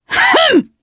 Demia-Vox_Attack2_jp.wav